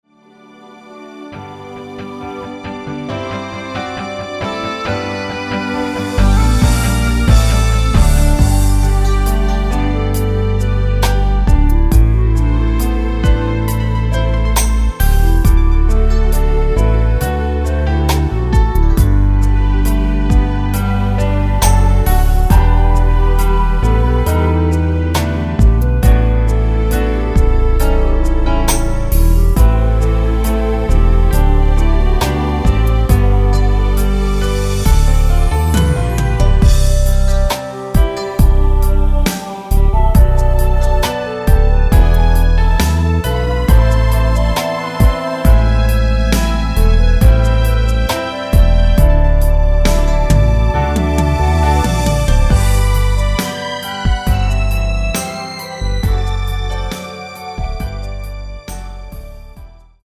발매일 1998.04 멜로디 포함된 MR 입니다.(미리듣기 참조)
앞부분30초, 뒷부분30초씩 편집해서 올려 드리고 있습니다.
중간에 음이 끈어지고 다시 나오는 이유는